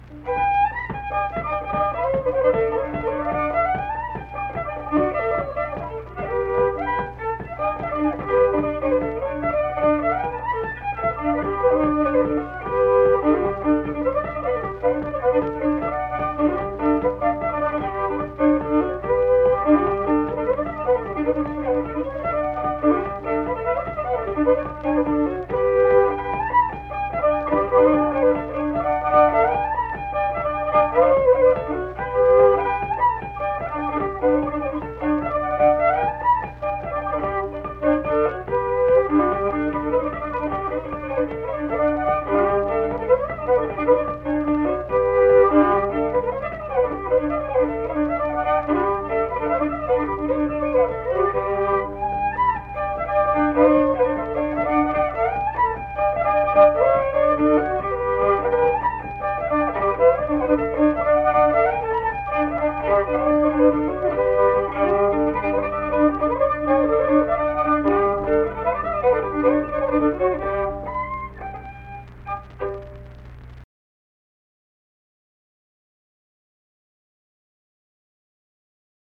Unaccompanied vocal and fiddle music
Instrumental Music
Fiddle, Voice (sung)
Mingo County (W. Va.), Kirk (W. Va.)